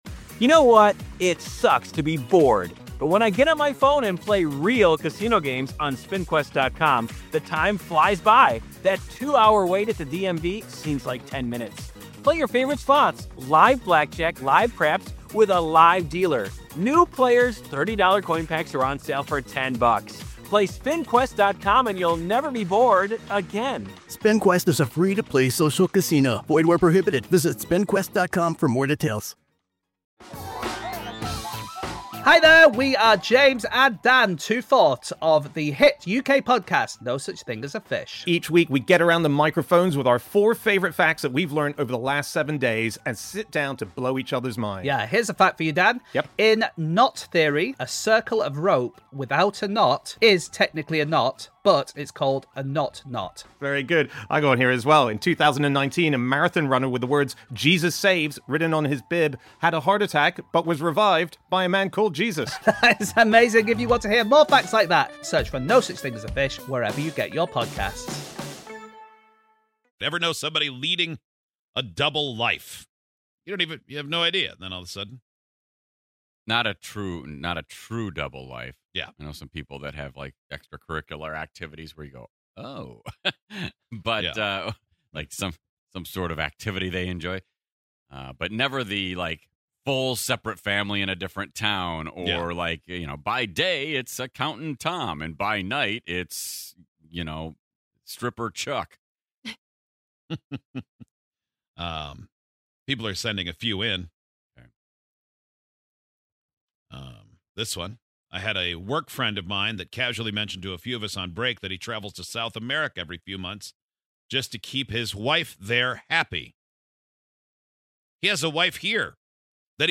Folks have been sharing stories of either them or someone they know living a double life.